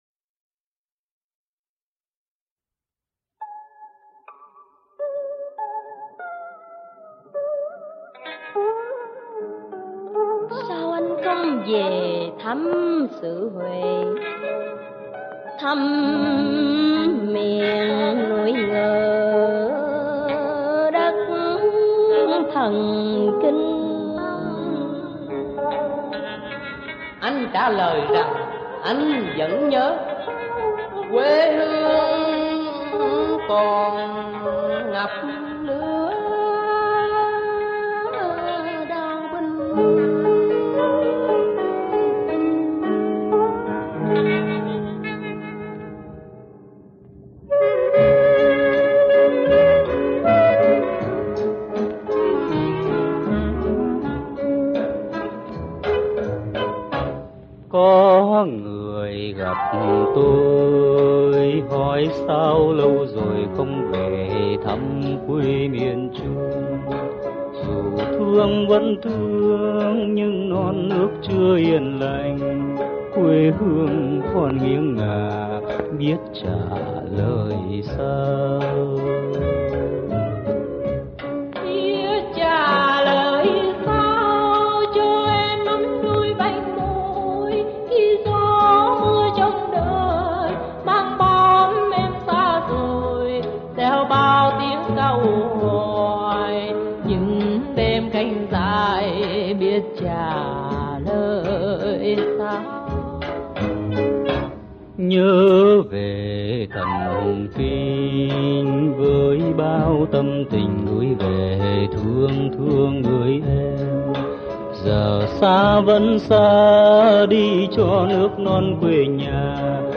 dĩa hát tân cổ